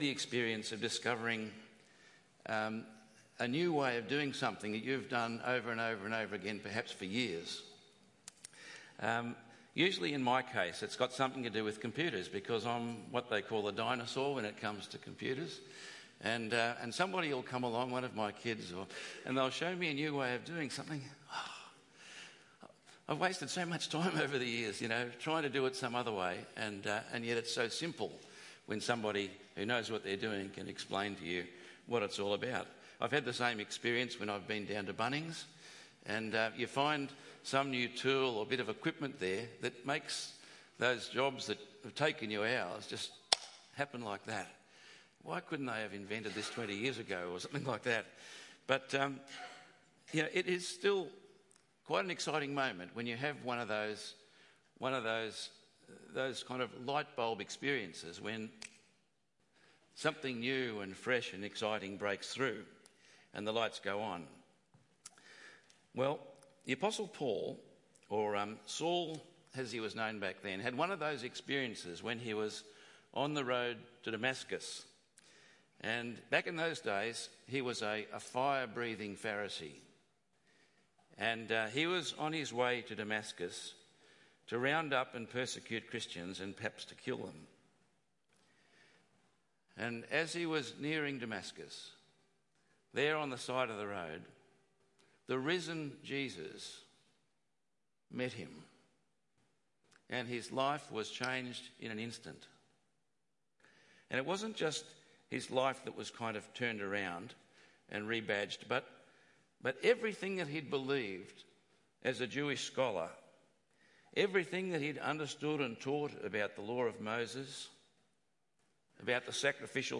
Why would you ever want to revert to a system that was broken, when Christ offers something far, far better! 2 Corinthians 3:7-18 Tagged with Sunday Morning